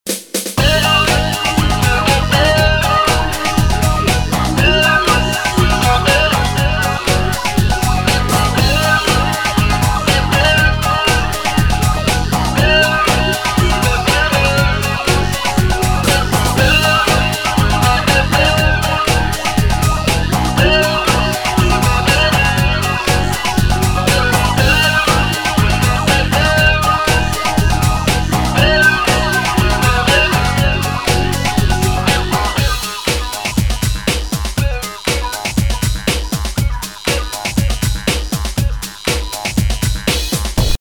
Висит на гитаре. В итоге получаем и не автовау, и не ENV...